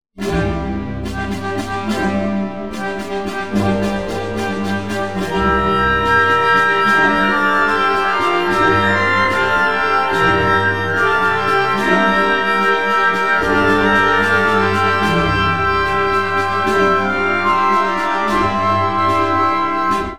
classical-20-hp.wav